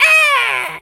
monkey_hurt_scream_01.wav